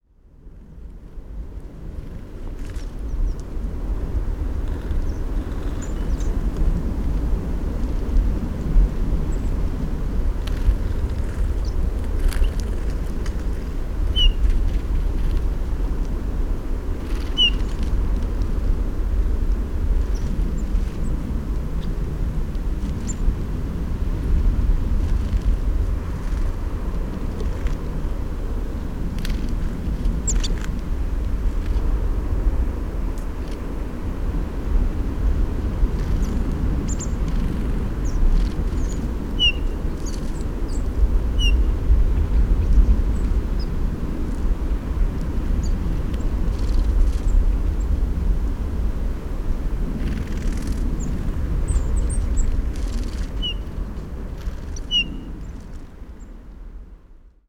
101218, Eurasian Bullfinch Pyrrhula pyrrhula, Blue Tit Cyanistes caeruleus, calls